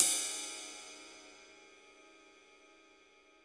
• Ride One Shot D# Key 06.wav
Royality free ride cymbal sample tuned to the D# note. Loudest frequency: 8737Hz
ride-one-shot-d-sharp-key-06-uOr.wav